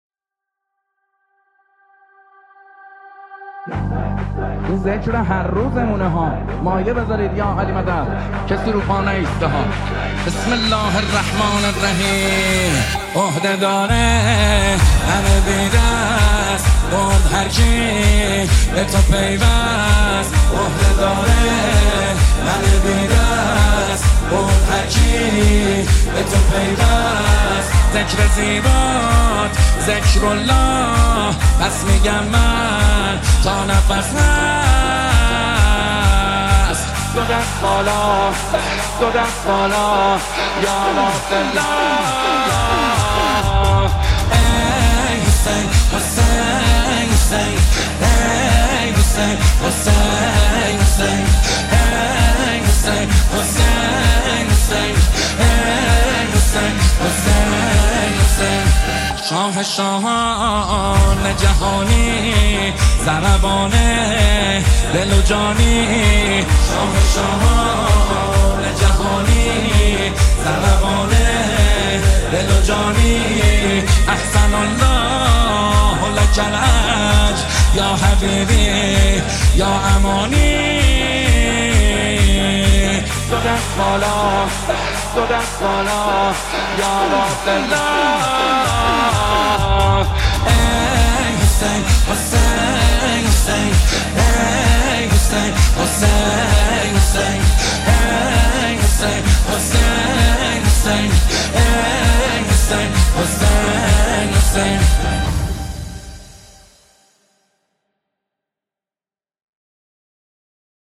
با نوای دلنشین